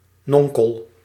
Ääntäminen
IPA : /ˈʌŋ.kəl/